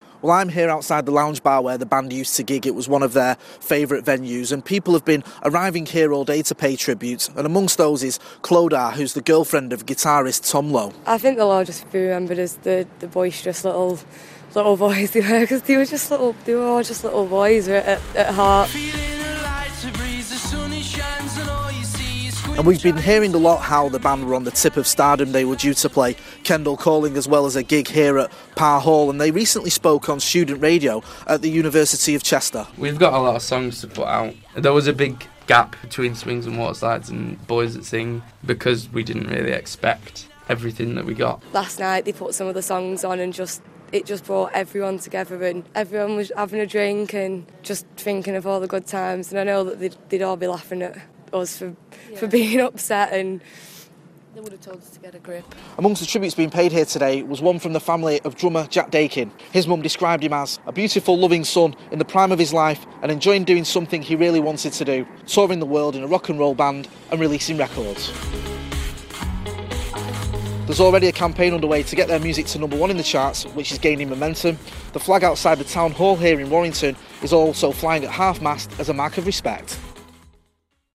Special report on Viola Beach